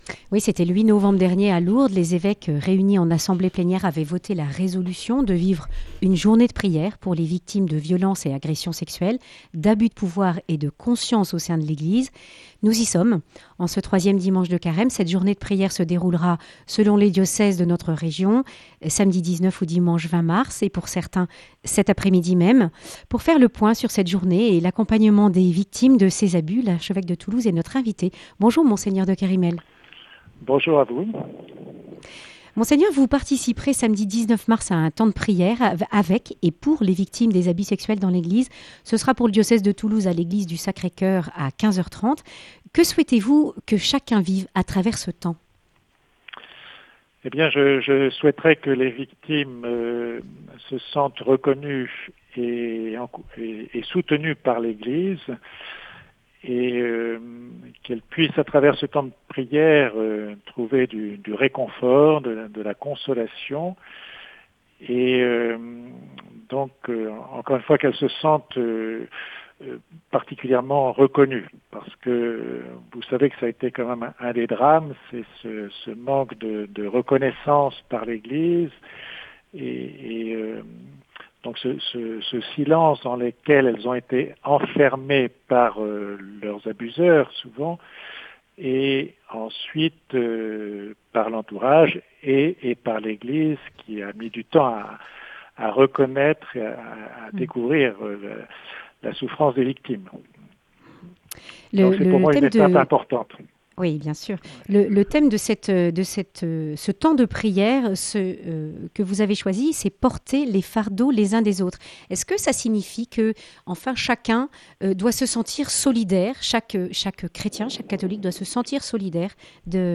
En ce troisième dimanche de carême, les diocèses se mobilisent pour prier à cette intention. Monseigneur de Kérimel, archevêque de Toulouse, présente les avancées (cellule d’écoute, formation des prêtres, mémorial...)
Le grand entretien